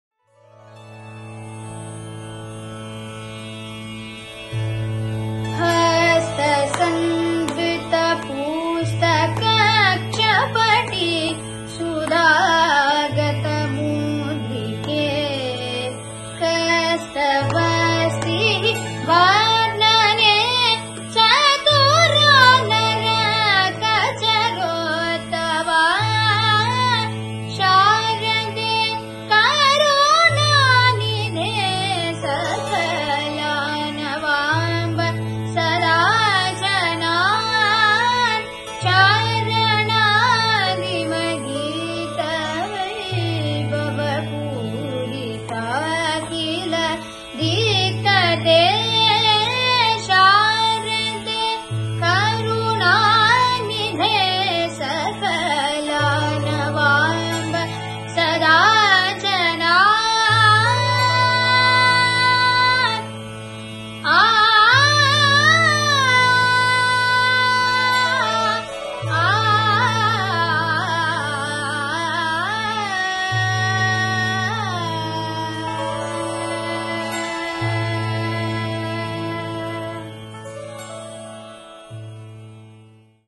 hamIr KalyANi
misra chAppu